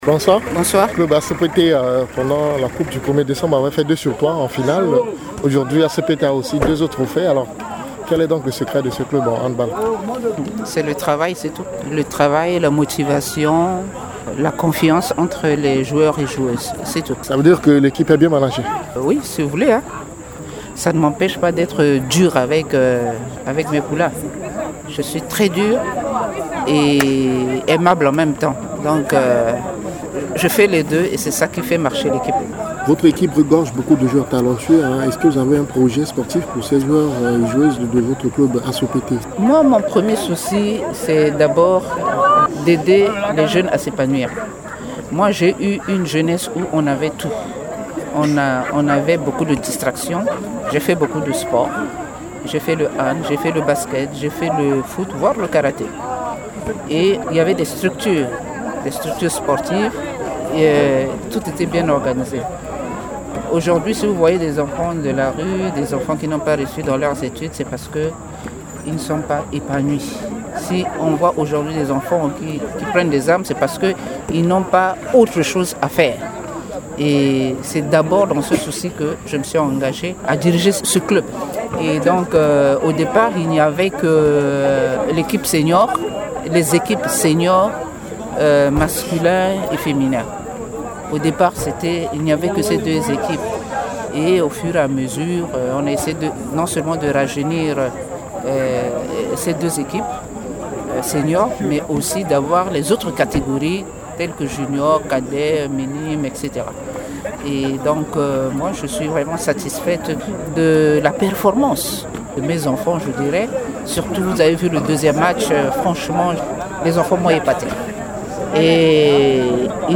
Elle appelle à l’unité des différente disciplines sportives et donne son avis sur le fonds du développement du sport jusque-là non adopté par le législateur. Dans cet entretien, occasion pour elle de présenter le secret de la prouesse réalisée par son club.